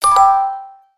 08470 music box collect ding
box checkpoint collect game grab music music-box notification sound effect free sound royalty free Sound Effects